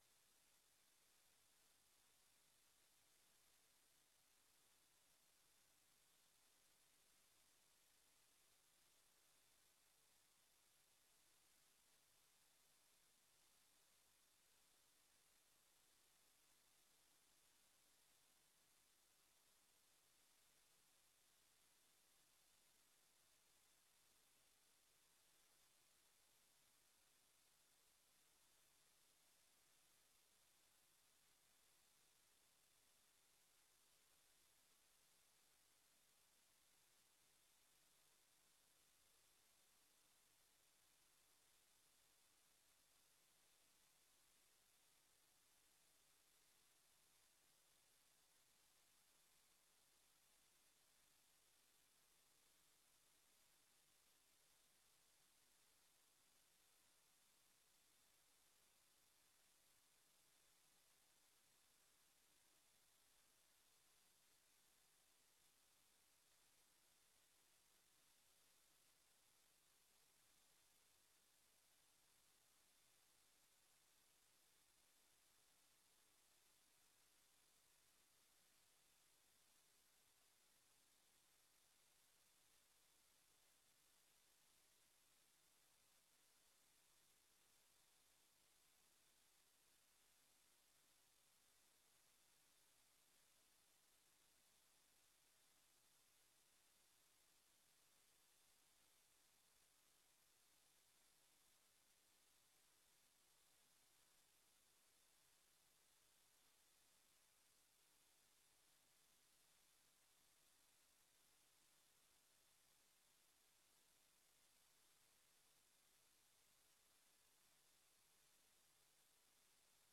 Werkbijeenkomst (openbaar) 13 oktober 2025 20:00:00, Gemeente Oude IJsselstreek
Locatie: DRU Industriepark - Conferentiezaal